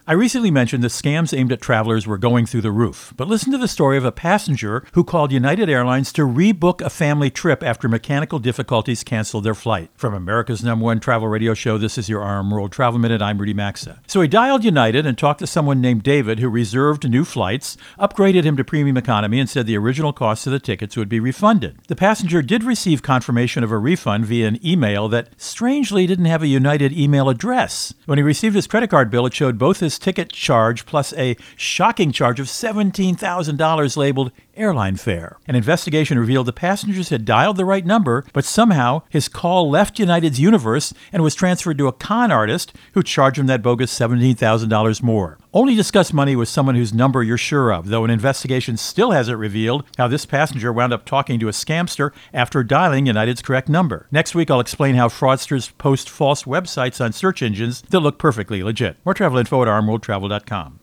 America's #1 Travel Radio Show
Co-Host Rudy Maxa | Scamming EZ Pass